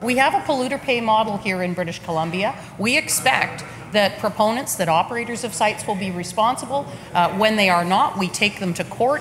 Minister of Environment Mary Polak responded.